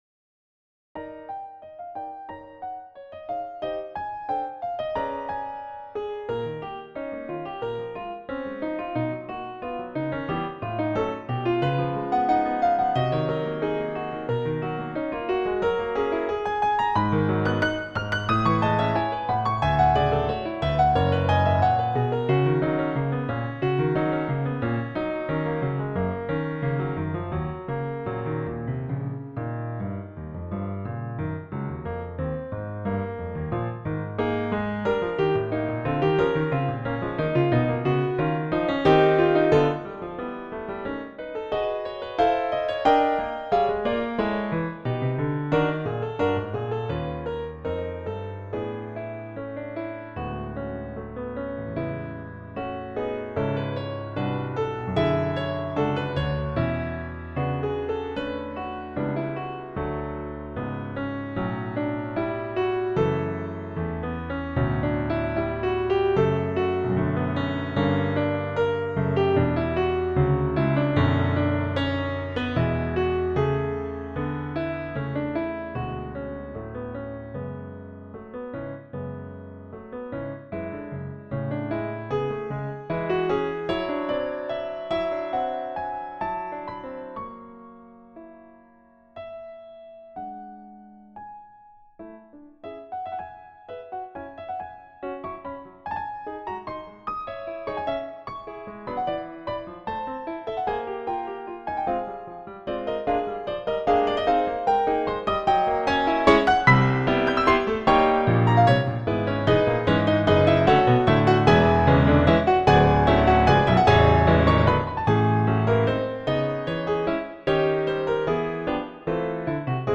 Piano Suite No. 1 | No. 1: Whimsy - Piano Music, Solo Keyboard - Young Composers Music Forum